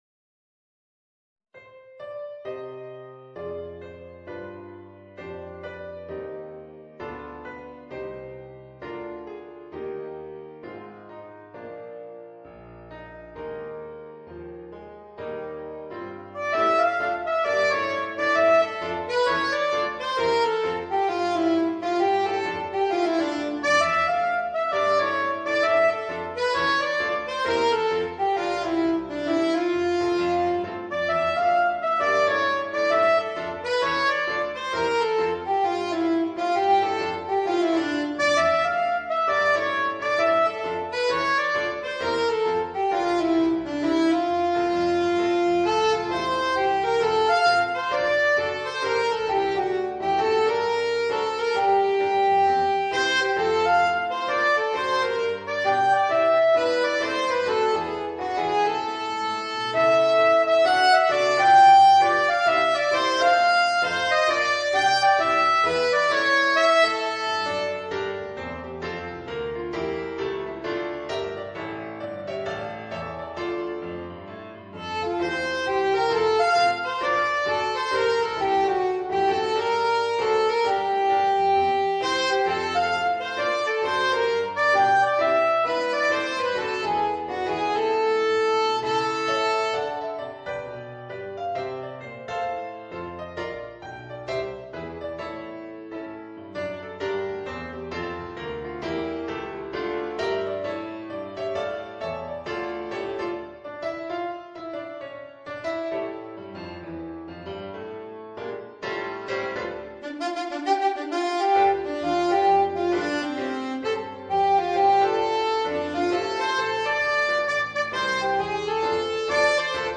Voicing: Alto Saxophone and Piano